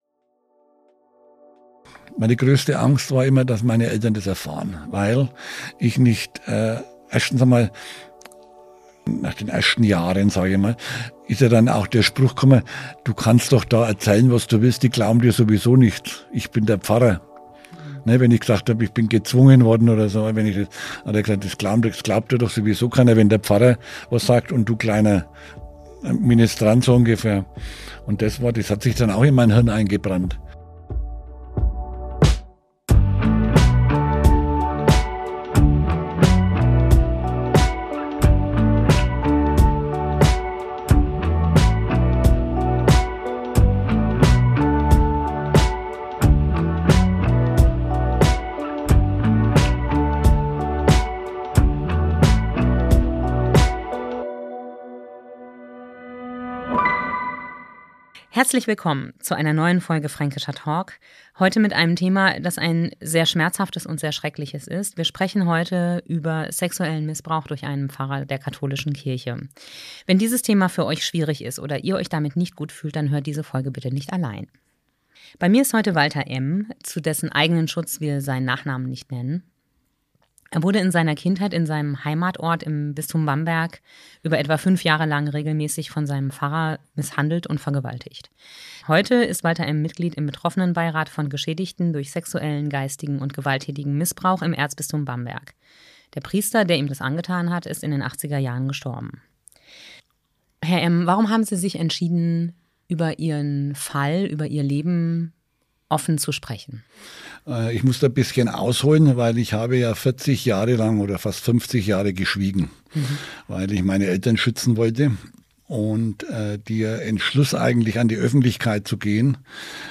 Wenn diese Themen Euch triggern oder Probleme bereiten, hört dieses Interview nicht oder nicht ohne Begleitung an.